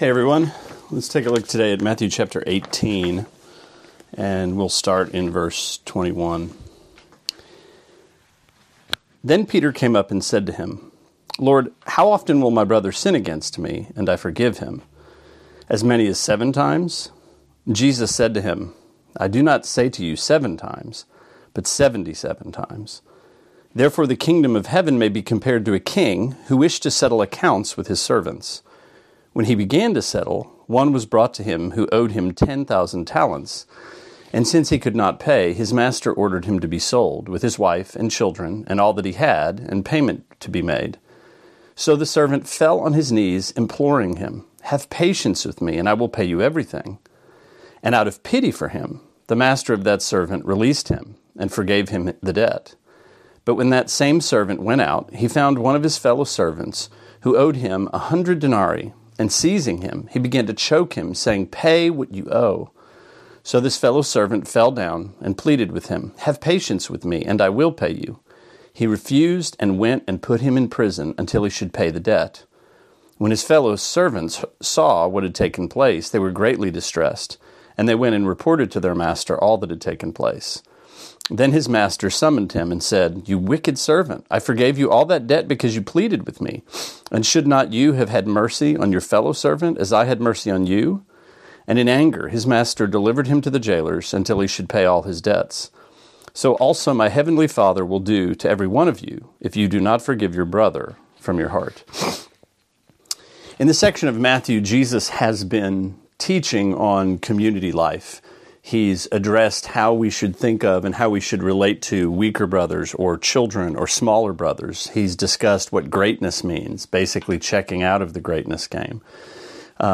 Sermonette 3/12: Matthew 18:21-35: 490 Years